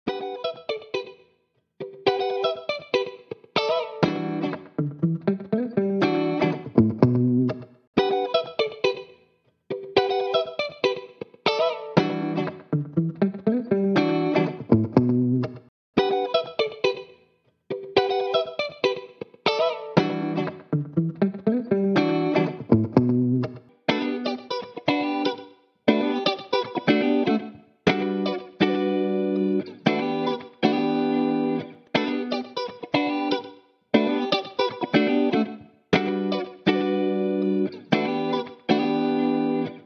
Made using Cakewalk with LANDR Chromatic plugin